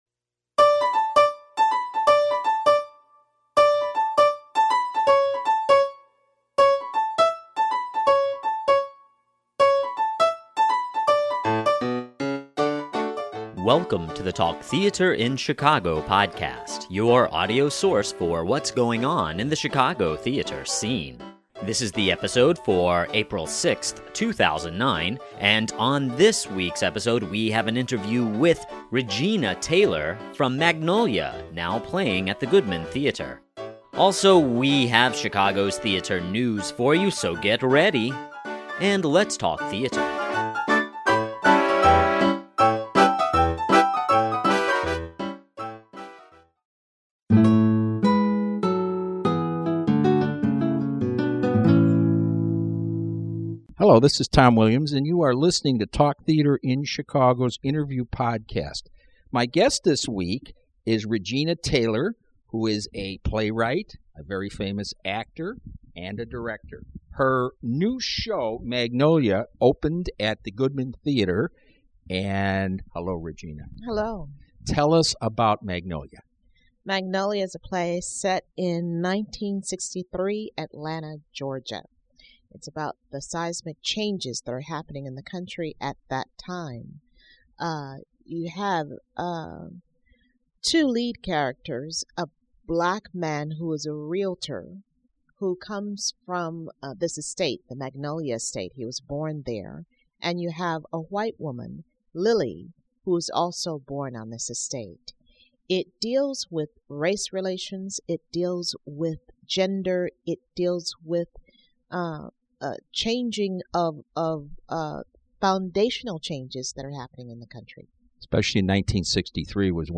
Regina Taylor Interview Podcast
This week on the Talk Theatre In Chicago podcast we have an interview with Regina Taylor, the author of Magnolia currently playing at the Goodman Theatre. She talks about the show as well as her very successful acting career.